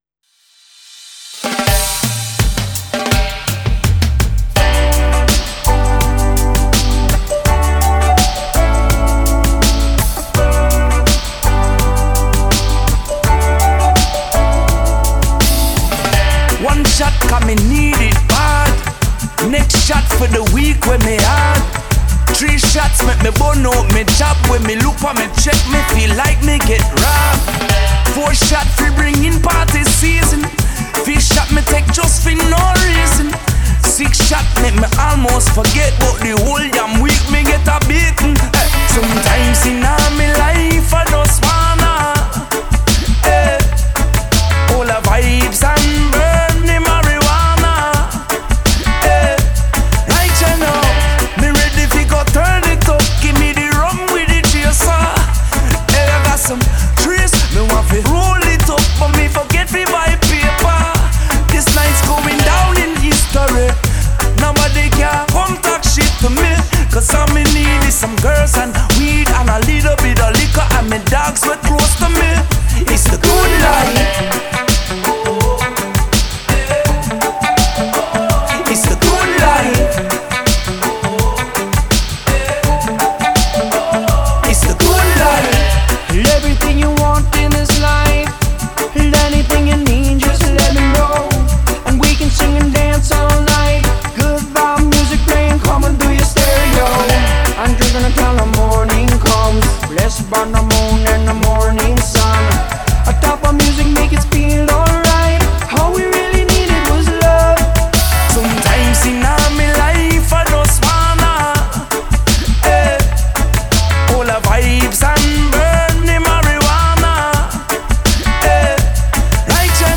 The song is a remix